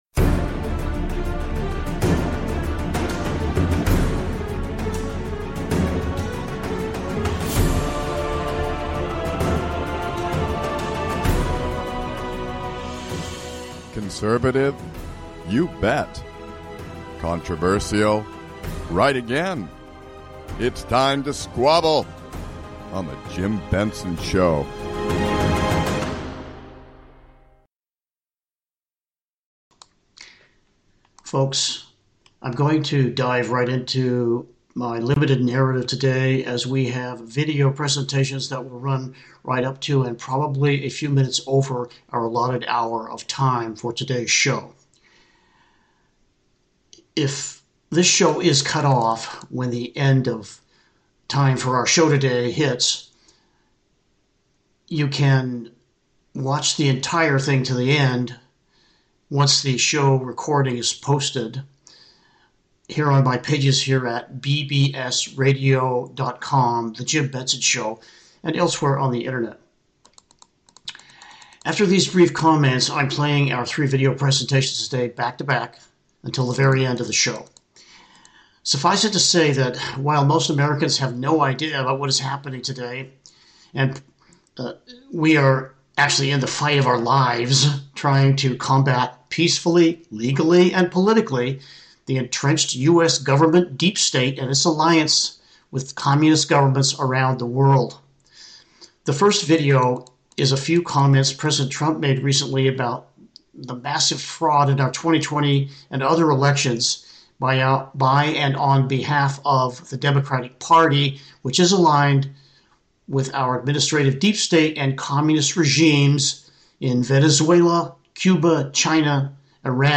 conservative talk radio done right, addressing the issues that concern you.